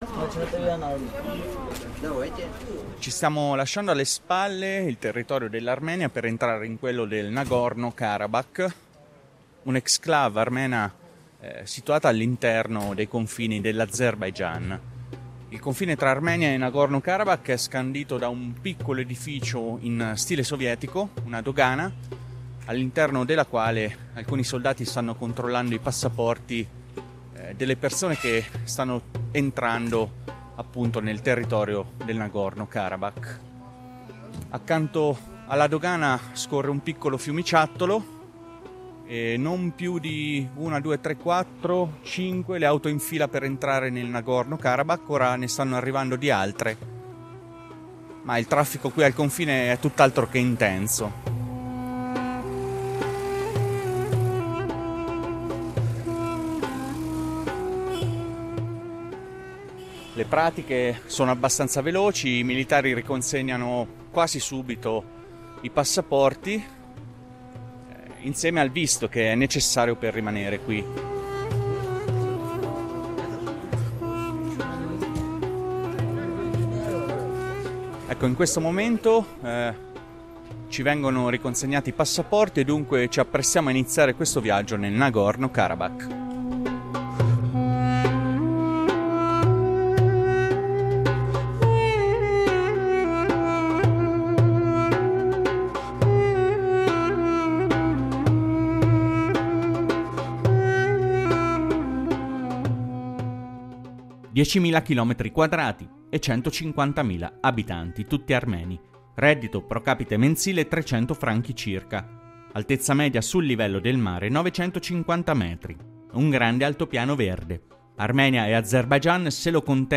In questo "Laser" ambientato nel Nagorno-Karabakh, ricostruiamo l'evoluzione di questo conflitto fino ai giorni nostri, con sopralluoghi alla linea di contatto e nei campi minati, interviste a veterani, politici e attivisti.